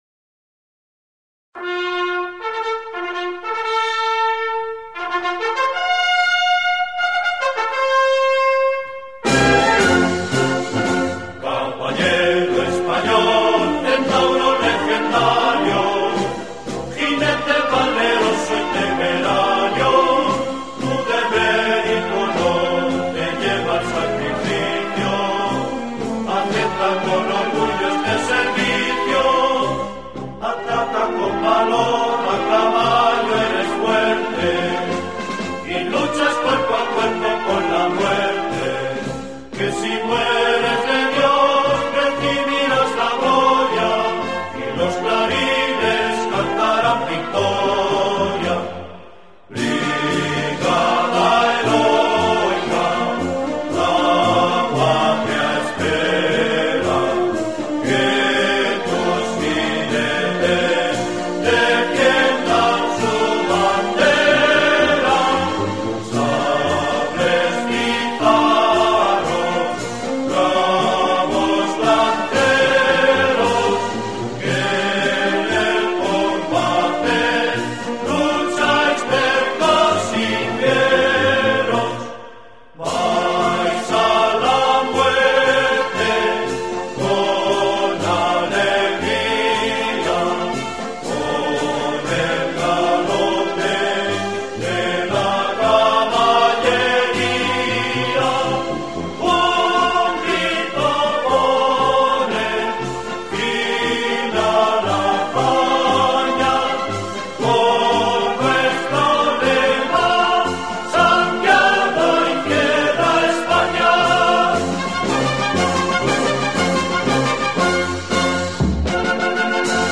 himno-de-caballeria.mp3